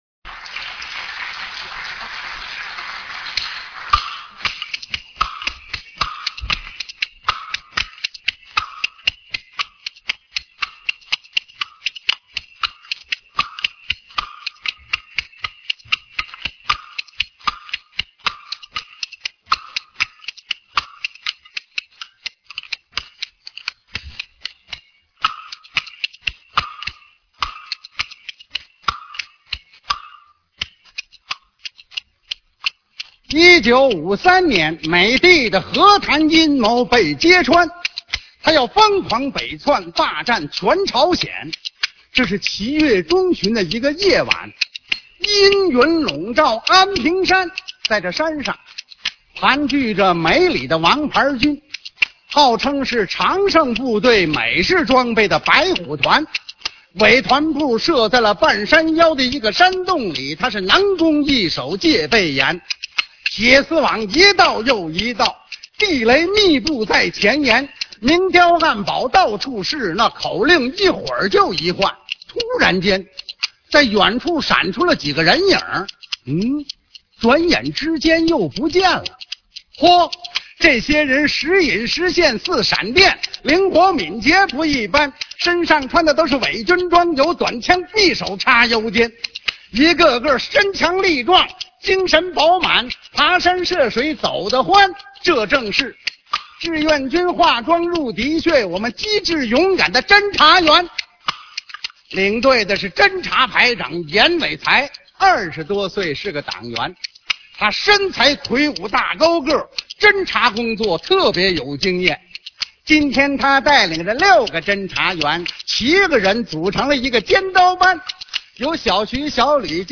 文革快板《奇袭白虎团》